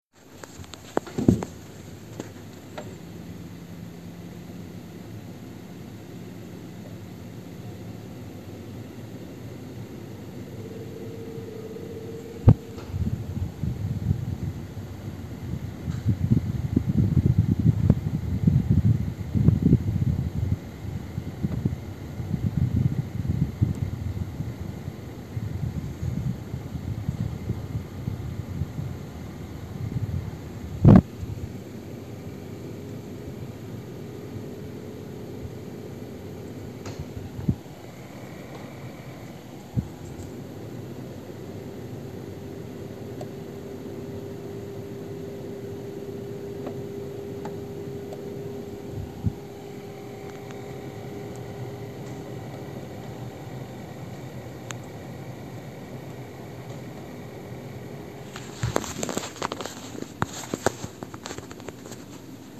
Je vous ai amplifié l'enregistrement de 20 dB.
Il y a d'abord le sifflement à 10 secondes de l'enregistrement (qui s'entend moins bien après parce que j'ai dû mettre le téléphone devant la soufflerie).
Ensuite il y a un bruit de moteur à partir de la 46ème seconde.
Pour les bruits, c'est effectivement le compresseur qu'on entend, et qui accélère peu à peu.
bruitclim-1-amplifie.mp3